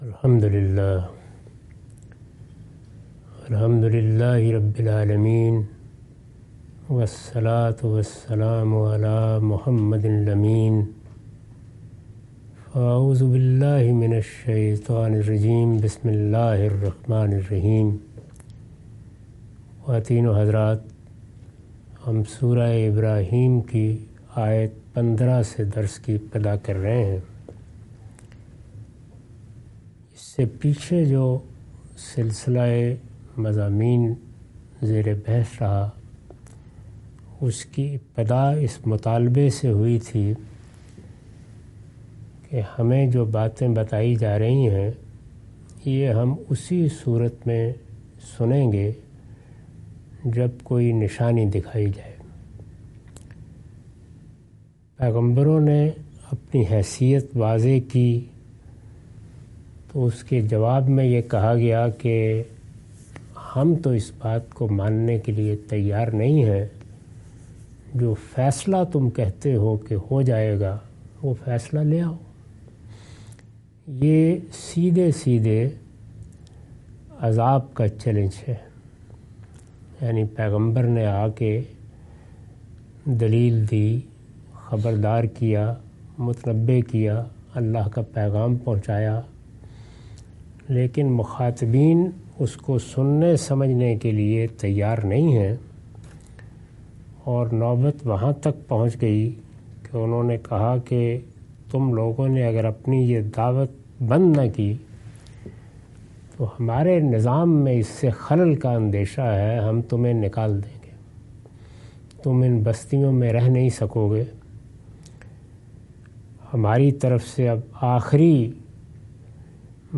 Surah Ibrahim- A lecture of Tafseer-ul-Quran – Al-Bayan by Javed Ahmad Ghamidi. Commentary and explanation of verses 15-21.